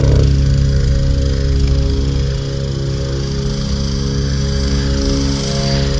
Звук беспилотника:
drone1.wav